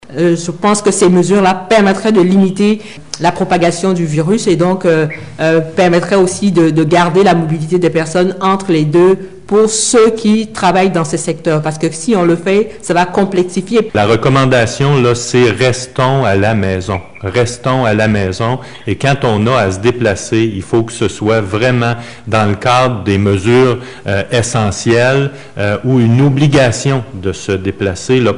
La question a été posée mercredi lors du point de presse des autorités régionales de la santé, à partir du constat que la Rive-Sud était encore peu touchée par la COVID-19 que la Rive-Nord.